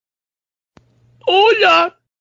hola homero Meme Sound Effect
Category: Meme Soundboard